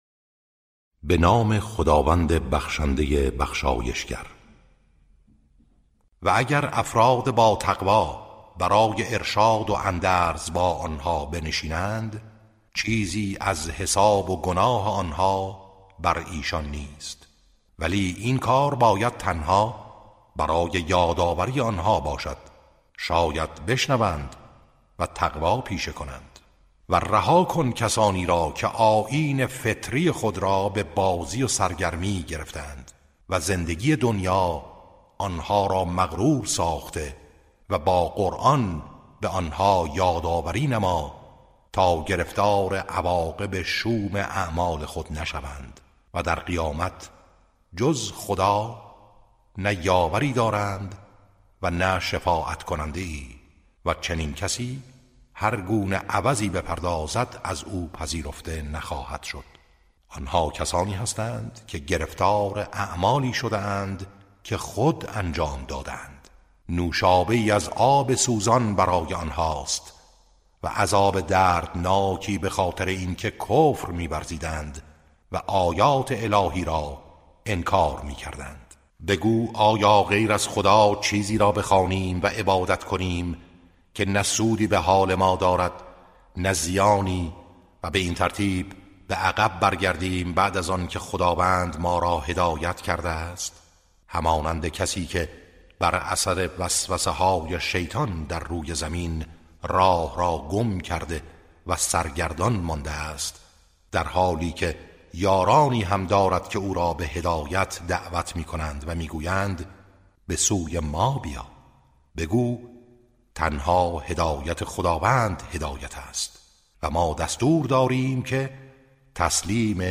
ترتیل صفحه 136 از سوره انعام(جزء هفتم)